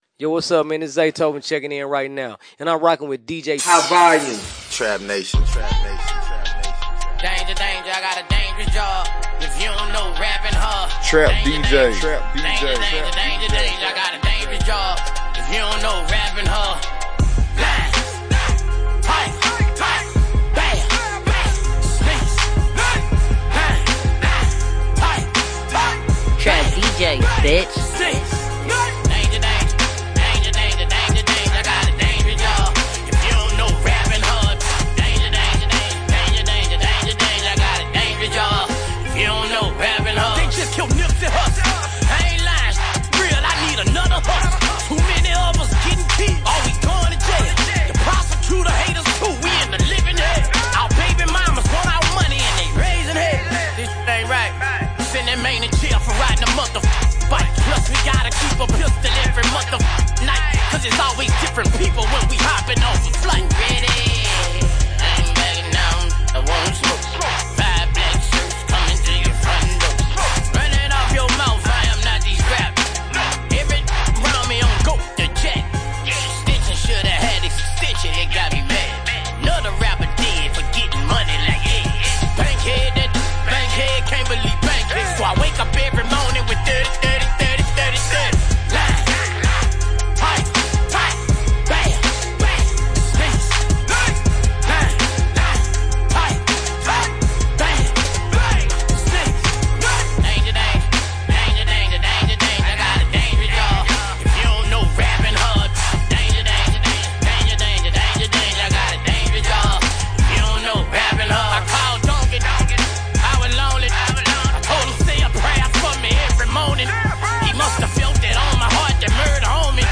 DJ Mixes